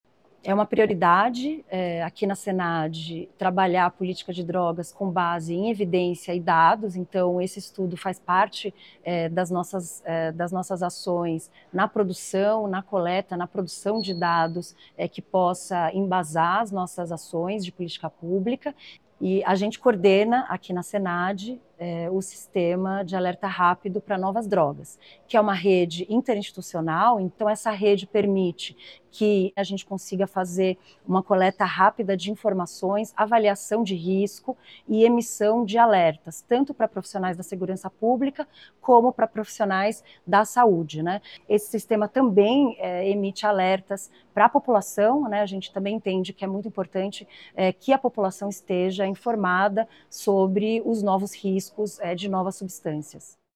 Marta Machado, Secretária Nacional de Políticas sobre Drogas, dá detalhes sobre sistema de alerta que permite celeridade na detecção de novas drogas — Ministério da Justiça e Segurança Pública